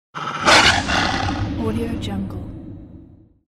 Wolf Attack Bouton sonore